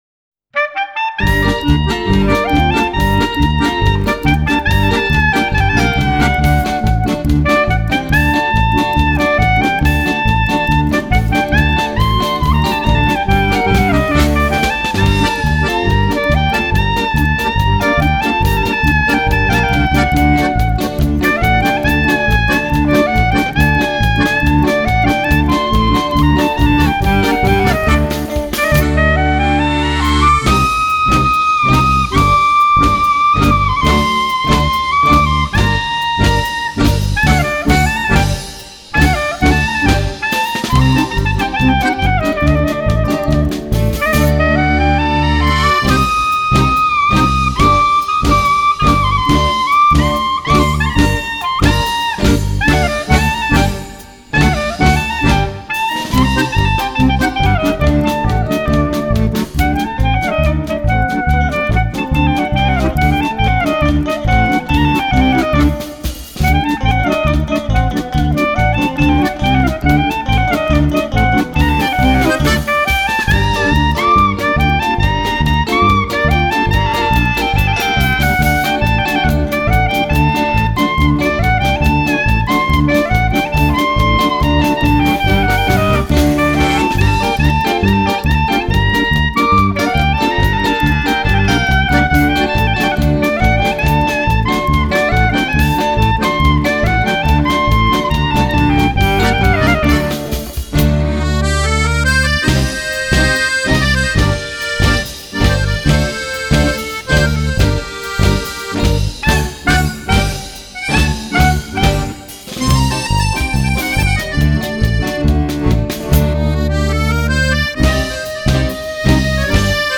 KlezFez - Klezmerband aus Berlin Traditionelle Klezmerstücke und jüdische und osteuropäische Musik. Vom schnellen Bulgar und dem mitreißendem Freilach bis zur langsamen Hora. Mit ganz eigenem Sound und interessanten Arrangements Mitreißend, druckvoll und sehr tanzbar, aber auch mit ganz leisen Tönen.
Besetzung: Gesang, Klarinette/Saxophon, Akkordeon, Gitarre, Bass und Schlagzeug Galeria de fotos: Canciones: 3-Silver_Wedding.mp3